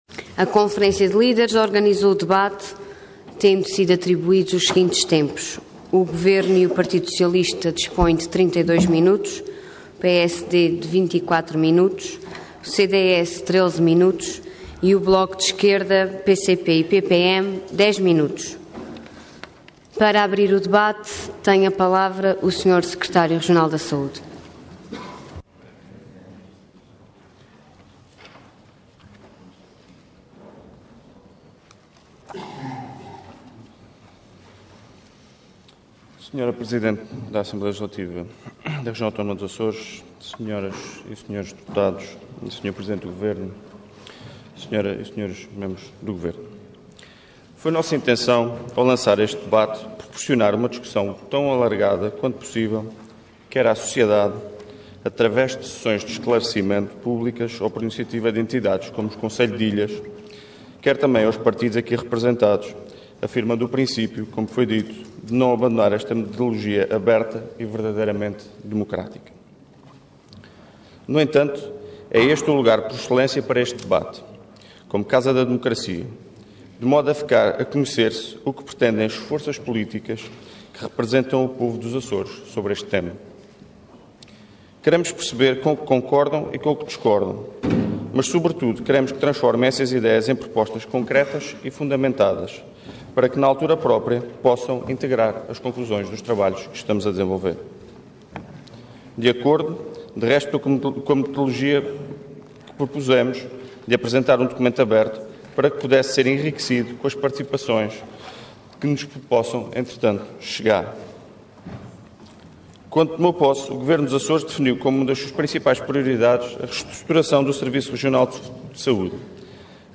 Parlamento online - Debate por iniciativa do Governo Regional, sobre a proposta de reestruturação do Serviço Regional de Saúde.